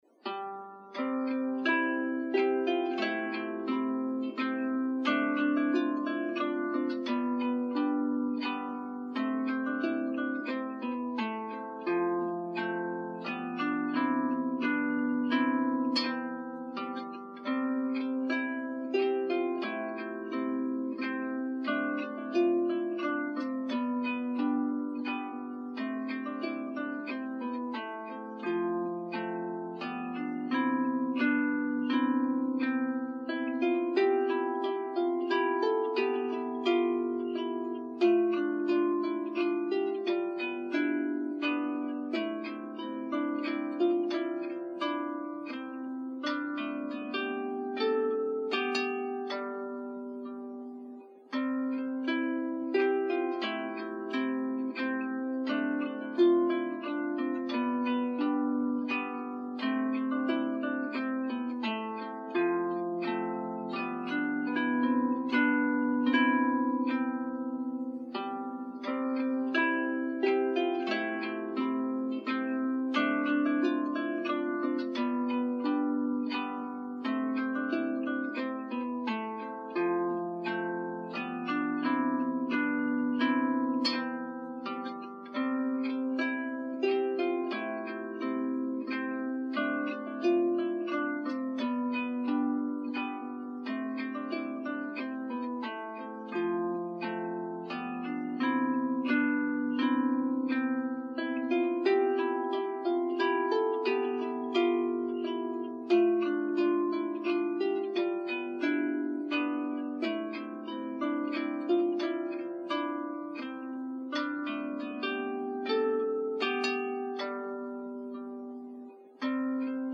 Double Strung Harp
Last year I played this traditional Welsh tune on lyre.